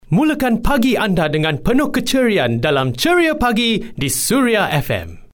Male Professional Voice Over Talent | VoicesNow Voiceover Actors
Male and Female Male voice over talent. Free voice over demos.